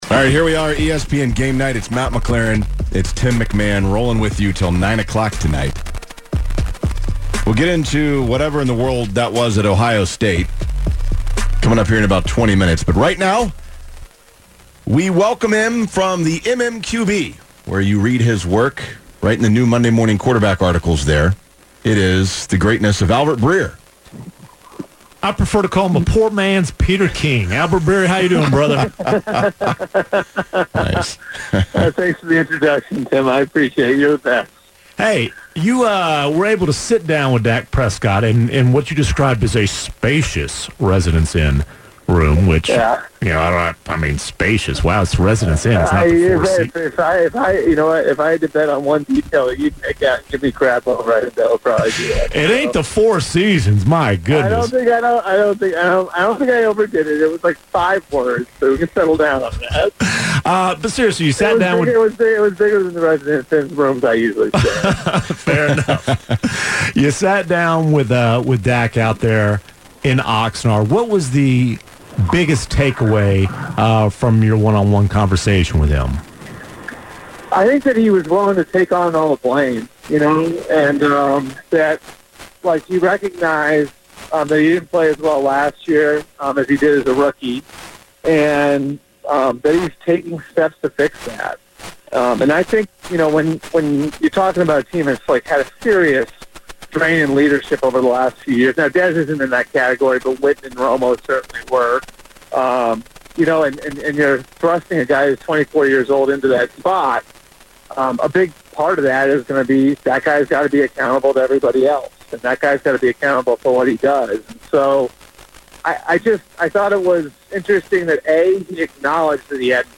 Game Night - Albert Breer joins the show to discuss Dak Prescott